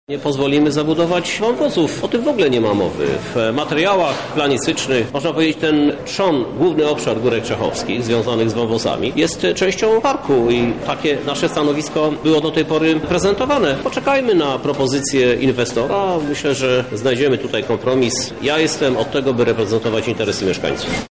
Eksperci dyskutowali dziś w ratuszu na temat ich walorów przyrodniczych. Inwestor chce zabudować 40% górek, a resztę przekształcić na tereny rekreacyjne.
Jak mówił prezydent Lublina, Krzysztof Żuk, plan zagospodarowania obszaru poznamy pod koniec roku: